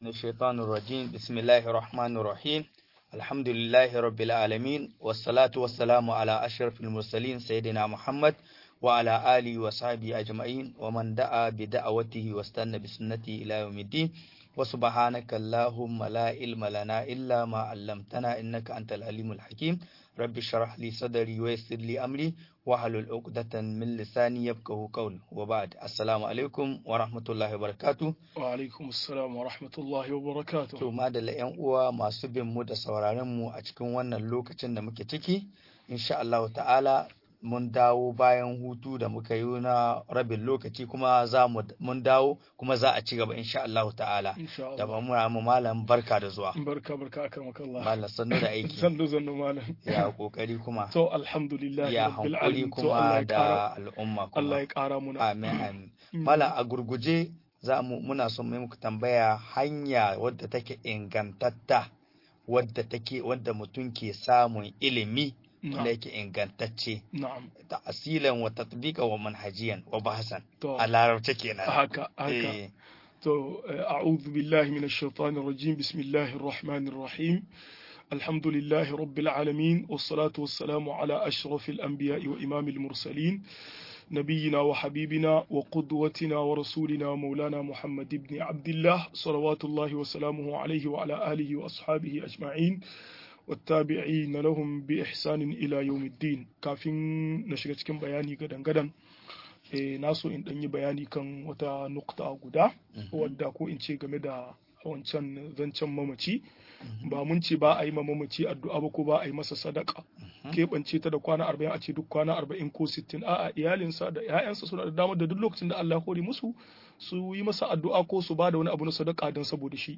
Bayani kan neman ilimi - MUHADARA